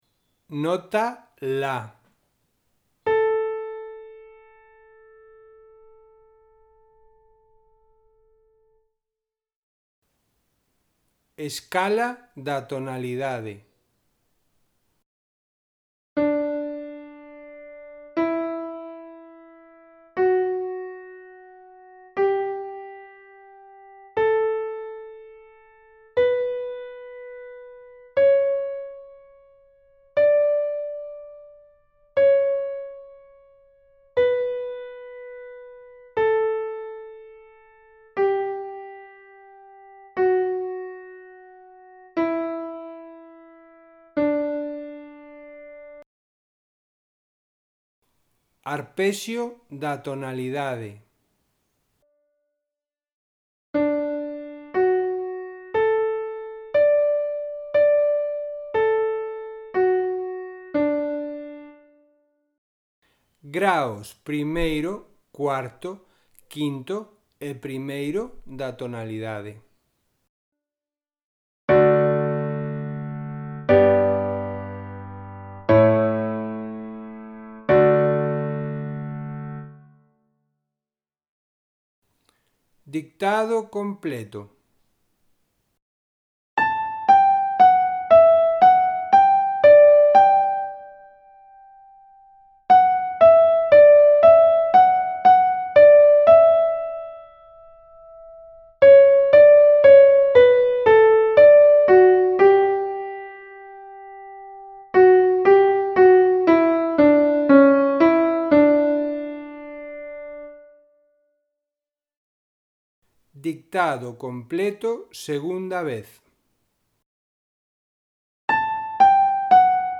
Ditados a 1 voz
Os audios seguen o mesmo esquema que os que xa fixestes: ditado completo, a escala, arpexio e os acordes, e a secuencia de repeticións.